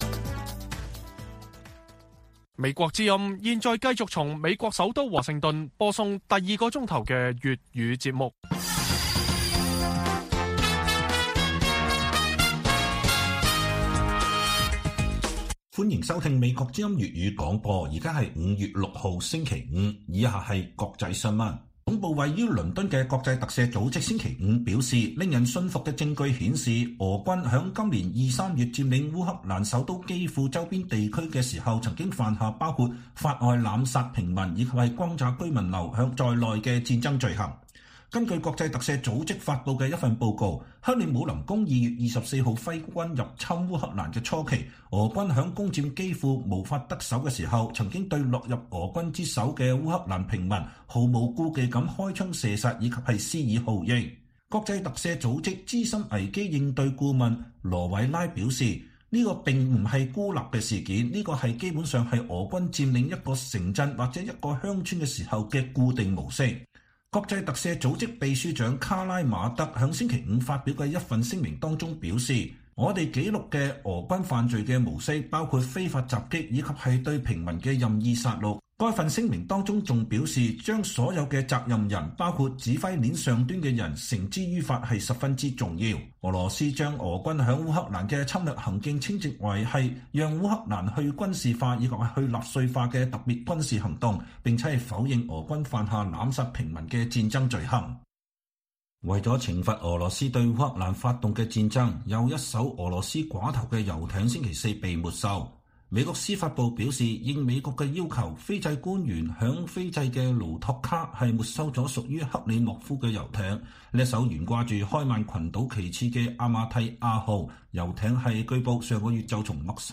粵語新聞 晚上10-11點: 國際特赦：確鑿證據顯示俄軍在基輔附近地區犯下戰爭罪行